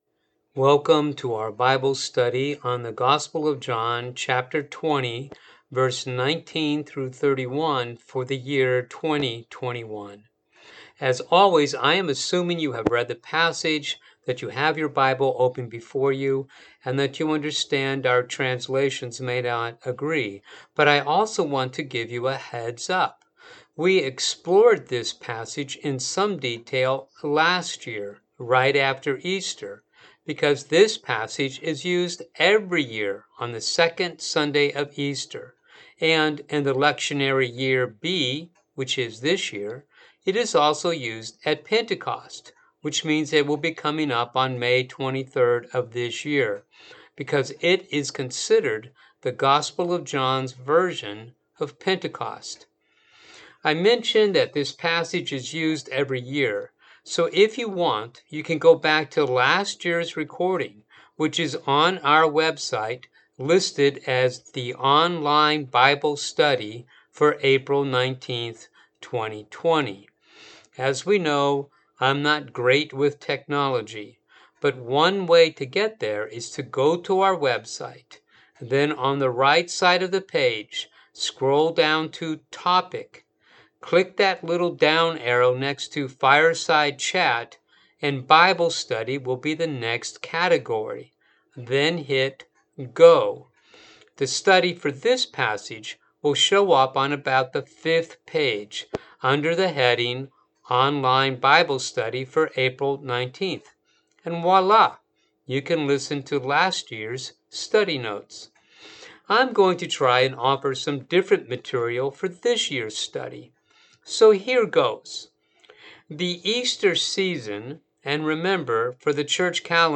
Bible Study Online